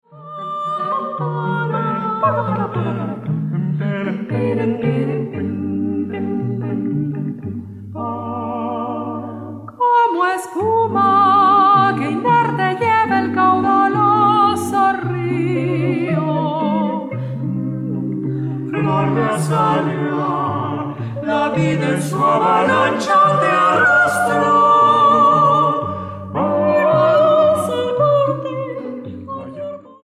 Sexteto Vocal